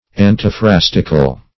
Search Result for " antiphrastical" : The Collaborative International Dictionary of English v.0.48: Antiphrastic \An`ti*phras"tic\, Antiphrastical \An`ti*phras"tic*al\, a. [Gr. ?.] Pertaining to antiphrasis.